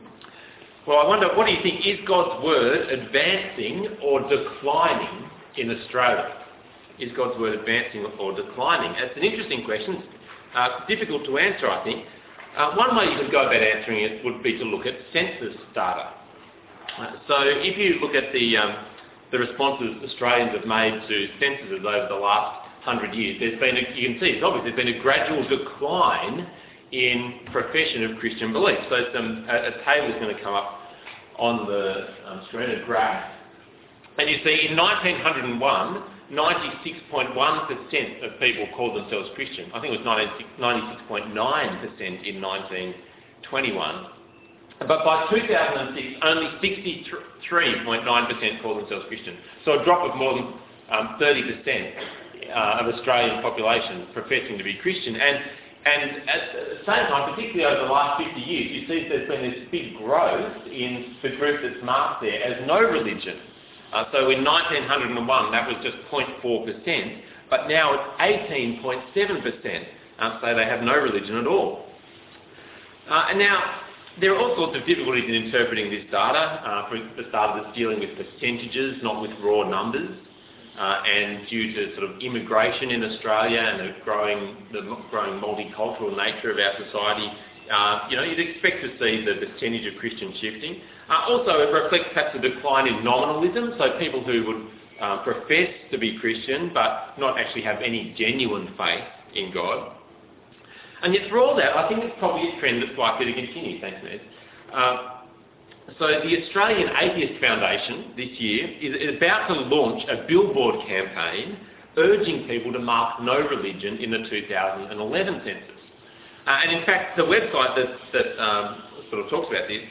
Passage: 1 Samuel 3:1-21 Talk Type: Bible Talk